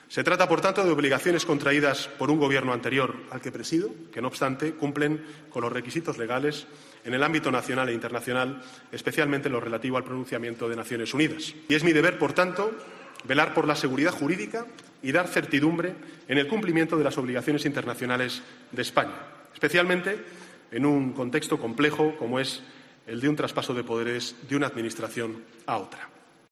"Tengo que estar hoy y aquí en la defensa de los intereses de España, de sus intereses estratégicos, que además están situados en zonas muy afectadas por el drama del desempleo", ha dicho en su comparecencia en el Pleno del Congreso, donde ha pedido al hemiciclo "altura de miras" para compartir esta visión.